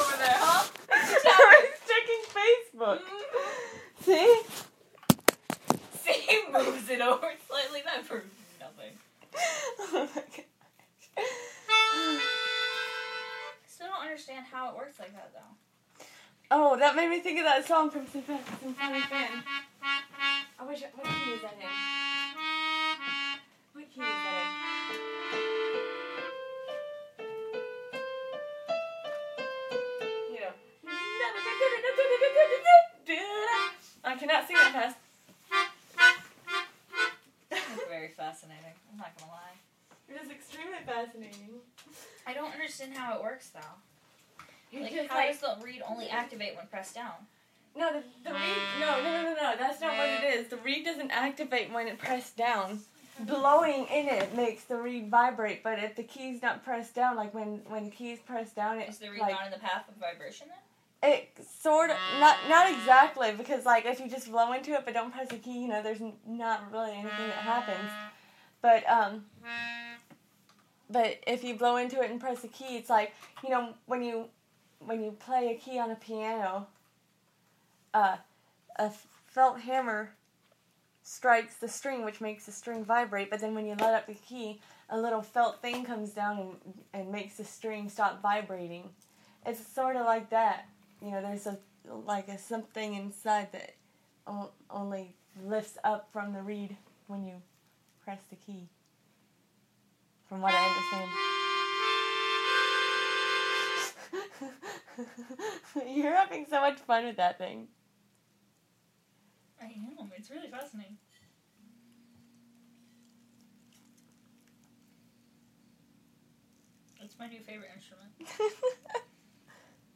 Melodicas and stuff